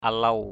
/a-lau/ (cv.) luw l~| [Cam M] (đg.) tru = hurler. howl, yell. asau aluw as~@ al~| chó tru = le chien hurle. the dog howls.